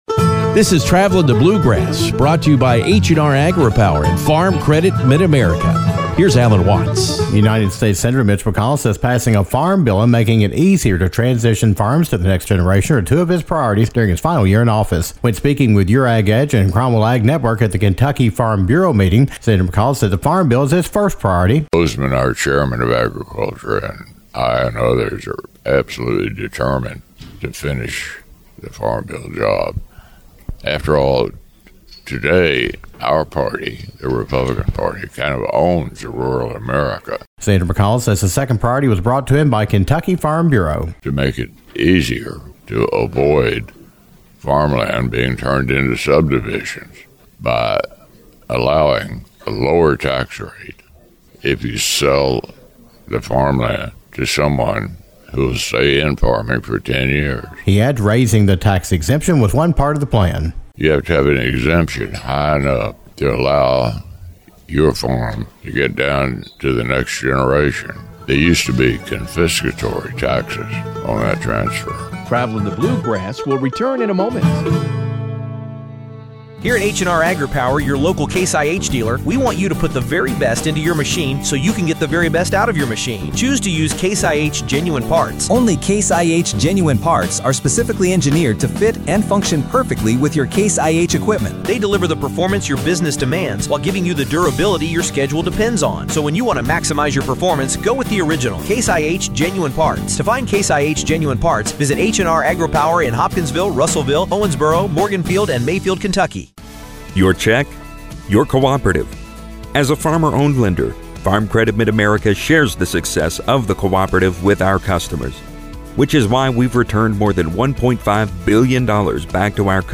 Passing a farm bill and continuing to work on the farmland transition initiative are two priorities for United States Senator Mitch McConnell who will retire next year. Senator McConnell discussed his priorities during the recent Kentucky Farm Bureau meeting in Louisville.